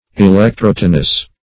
Electrotonous \E`lec*trot"o*nous\, a.